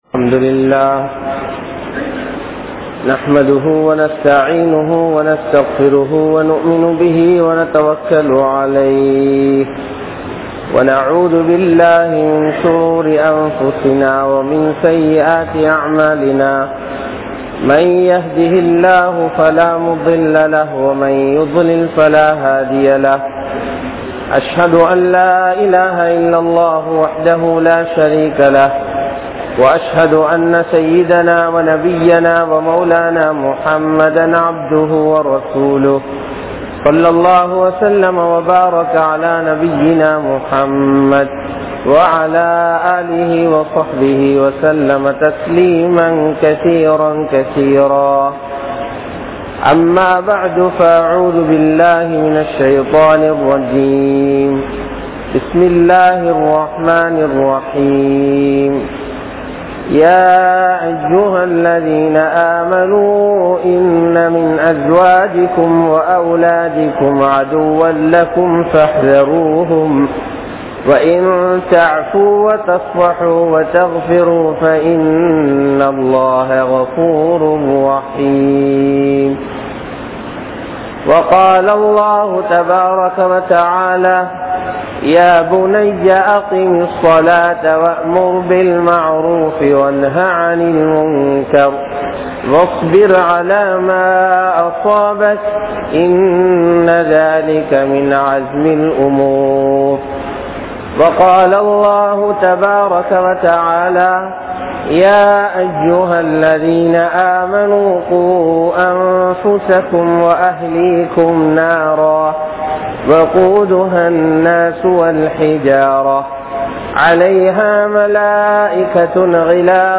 How To Guide Our Youth | Audio Bayans | All Ceylon Muslim Youth Community | Addalaichenai
Aluthgama,Dharga Town, Grand Jumua Masjith